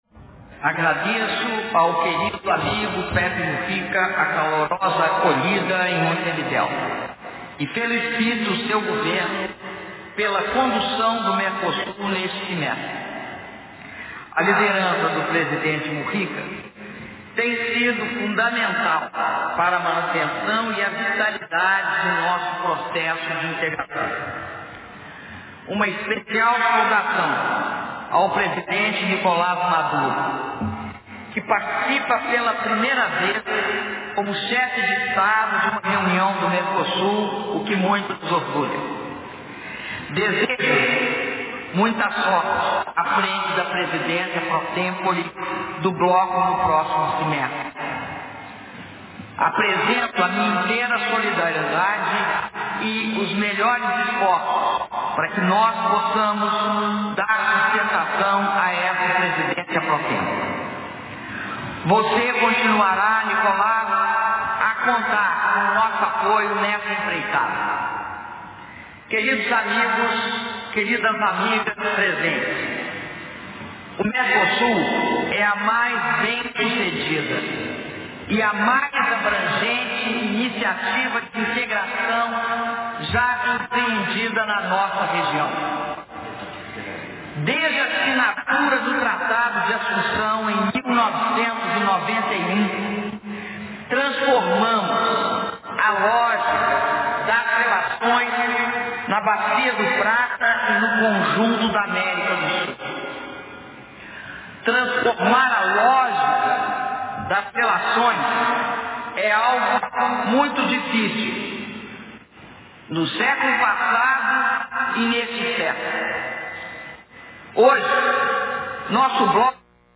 Discurso da Presidenta da República, Dilma Rousseff, durante reunião de Cúpula dos Estados Parte e Estados Associados do Mercosul e convidados especiais
Montevidéu-Uruguai, 12 de julho de 2013